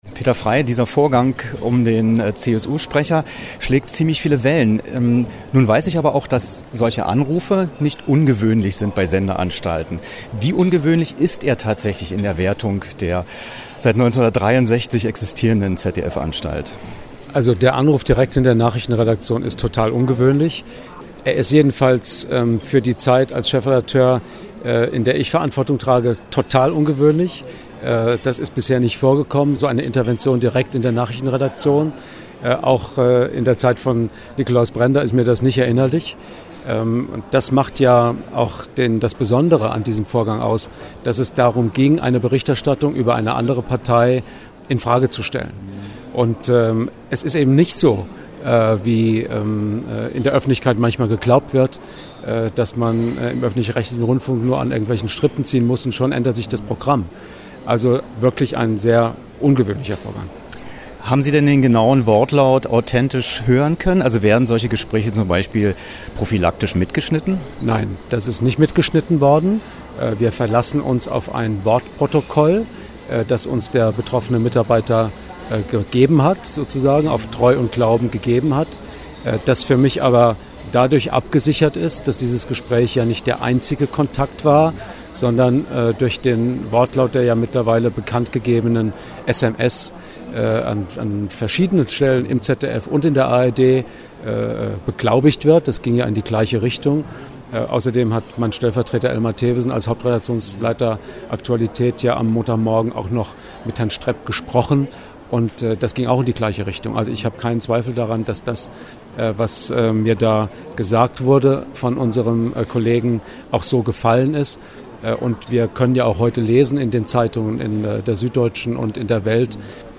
Wer: Horst Seehofer, Ministerpräsident des Freistaates Bayern
Was: Interview über Nichteinmischung
Wo: München, Messe